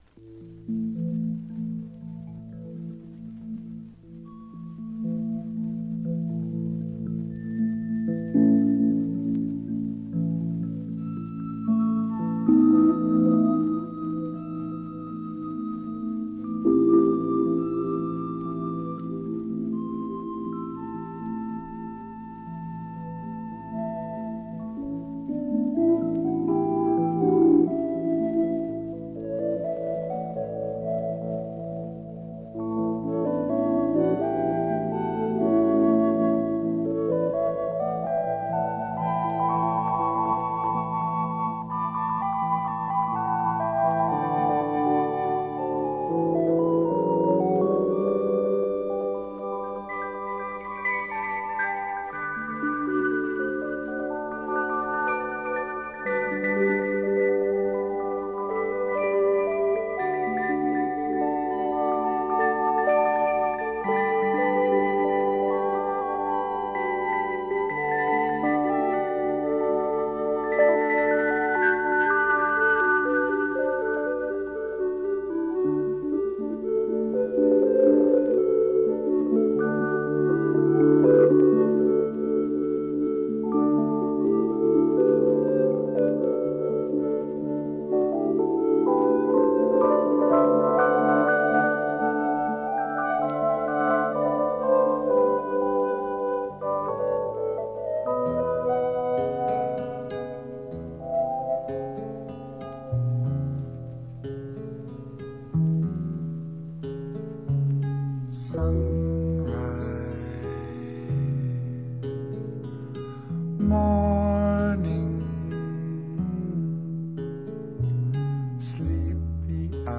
some recent discoveries in soft rock, psych and folk realms
Americana Folk International Soul